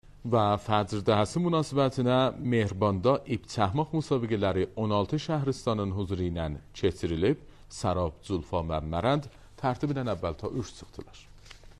گزارش وضع هوا